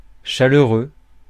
Ääntäminen
Tuntematon aksentti: IPA : /ɪkˈsaɪtɪd/